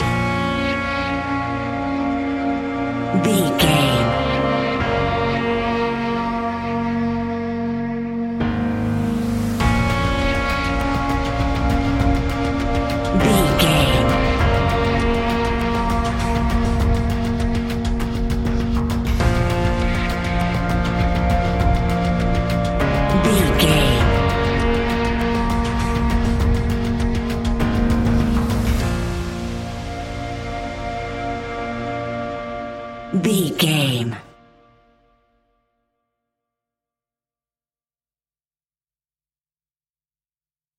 In-crescendo
Thriller
Aeolian/Minor
ominous
eerie
unnerving
horror music
Horror Pads
horror piano
Horror Synths